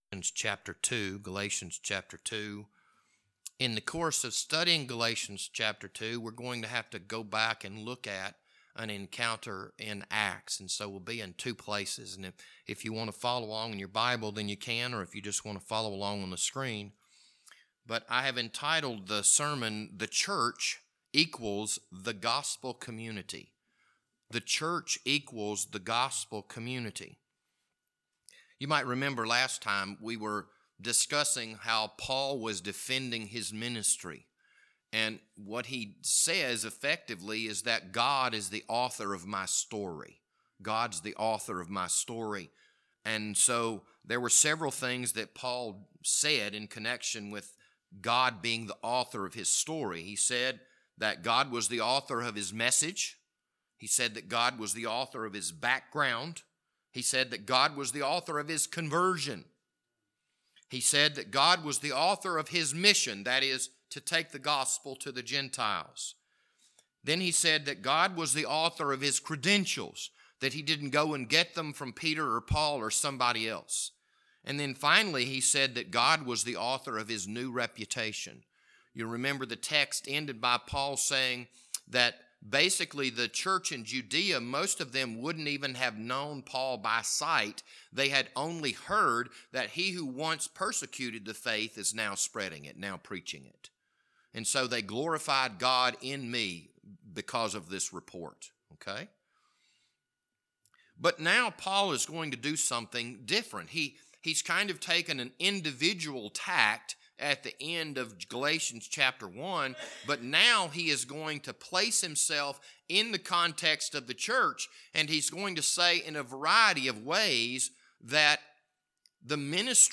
This Sunday evening sermon was recorded on March 27th, 2022.